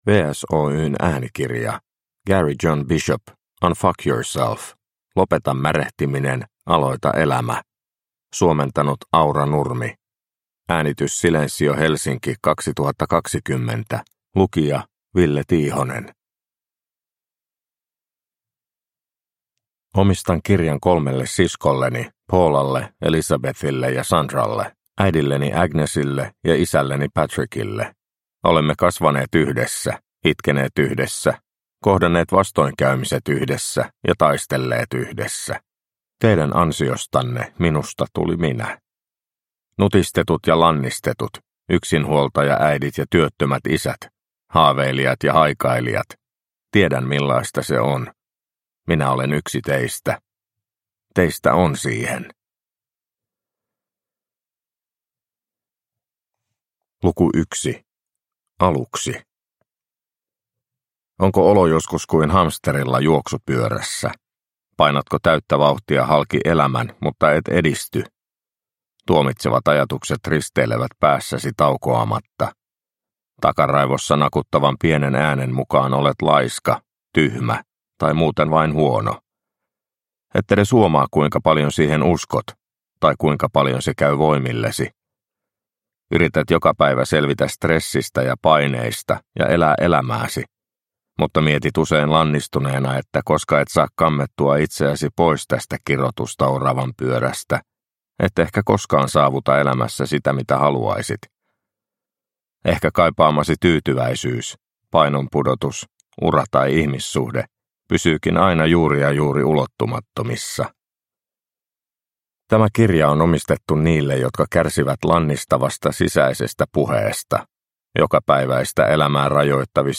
Unfu*k yourself – Ljudbok – Laddas ner